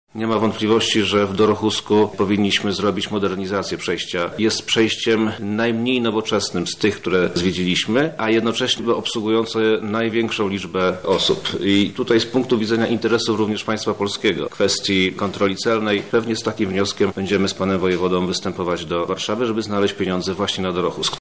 – mówi wojewoda Przemysław Czarnek.